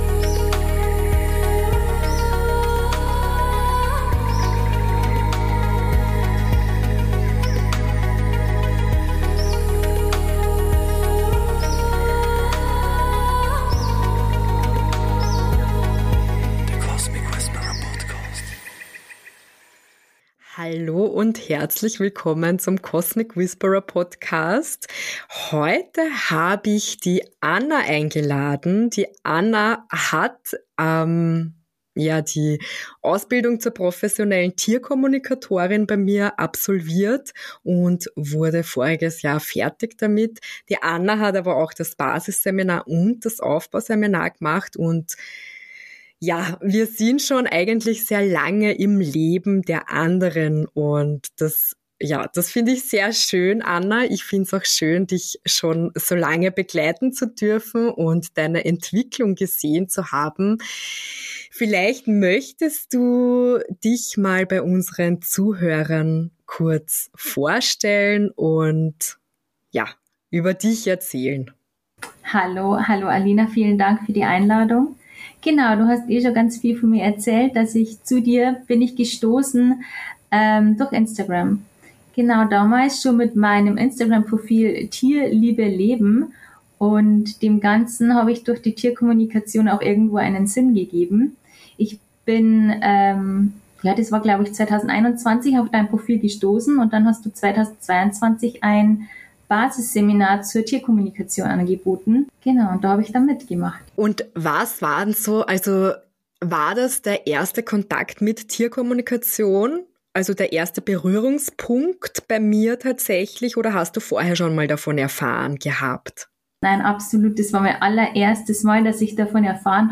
Im Gespräch mit Cosmic Whisperer Absolventin